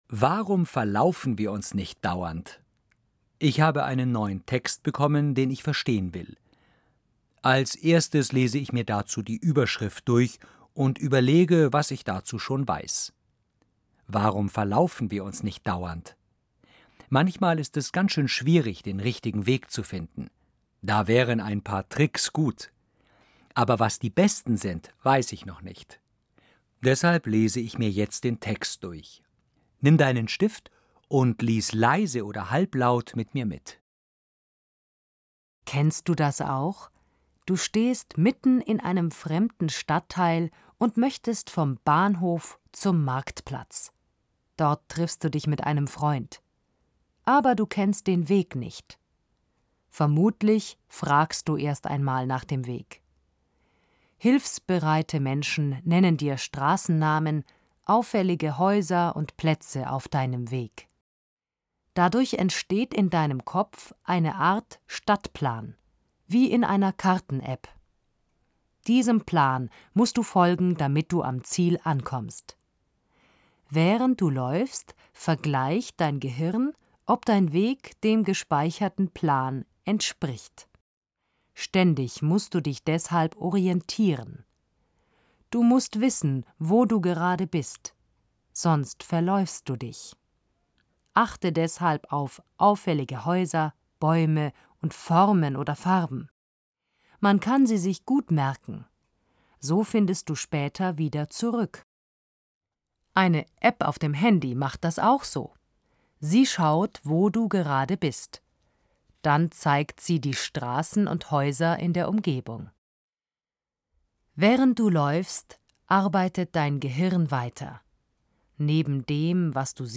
Hörtexte